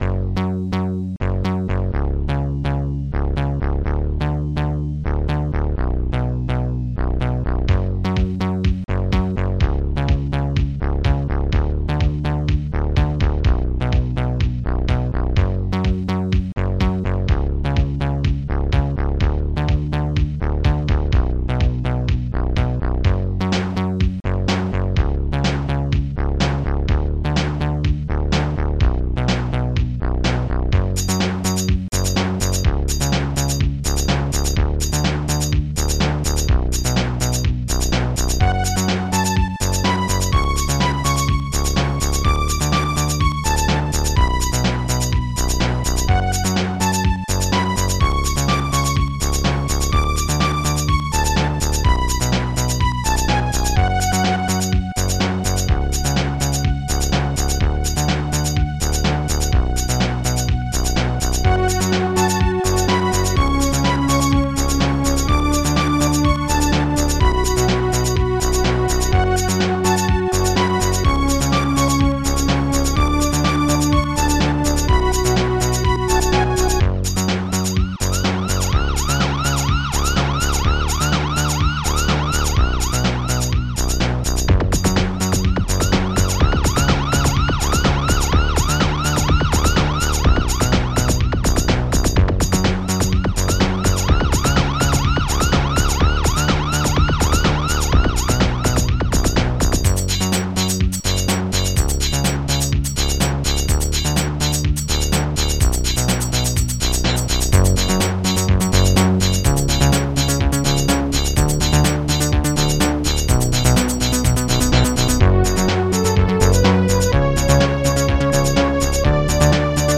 Protracker and family
st-12:photon-bass
st-12:photon-snare2
st-12:photon-string
st-12:photon-laser
st-12:photon-tom